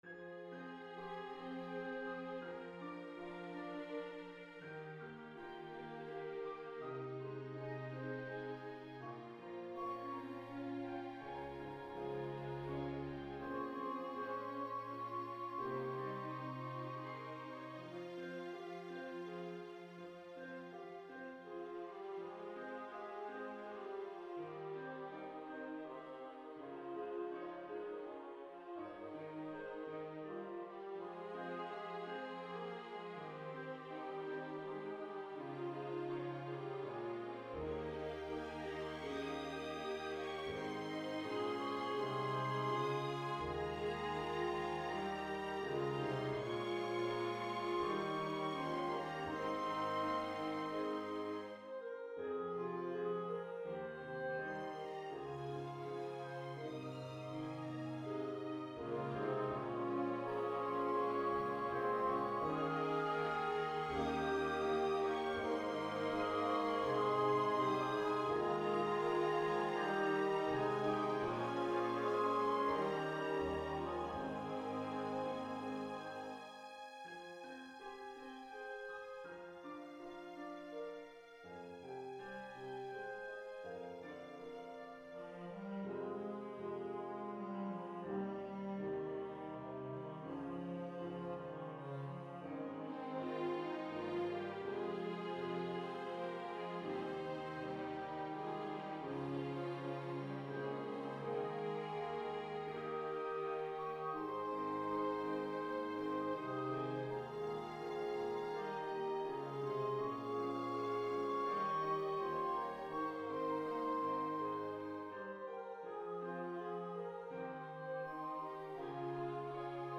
fully orchestrated version
Flute
Bb Clarinet
Bass Clarinet
Horn in F
Trombone
Tuba
Violin I
Violin II
Viola